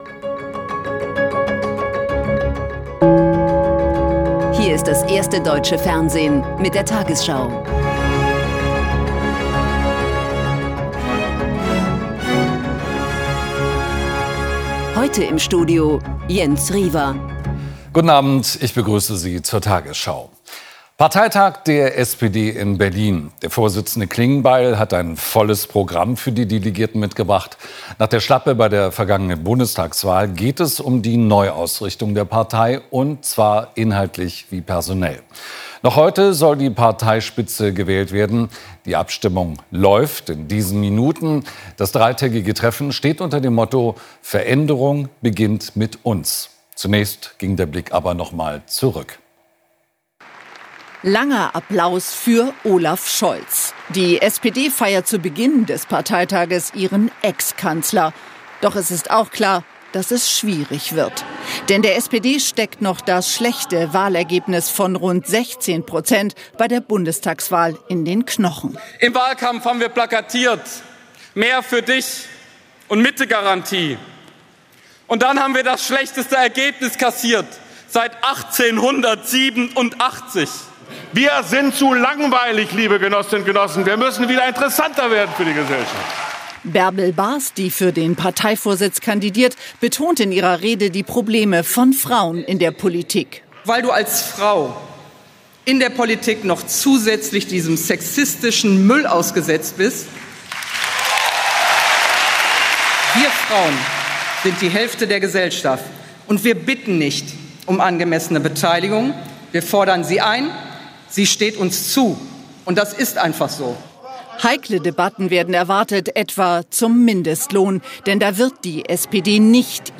Die 20 Uhr Nachrichten von heute zum Nachhören. Hier findet ihr immer die aktuellsten und wichtigsten News.